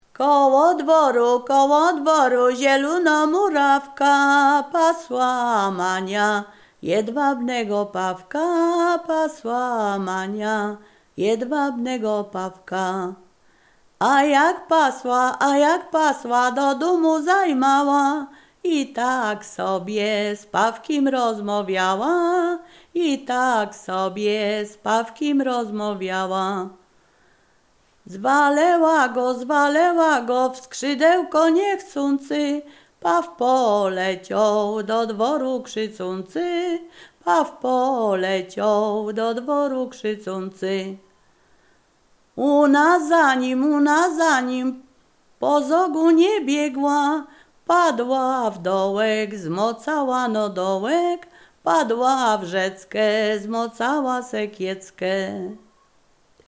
Ziemia Radomska
liryczne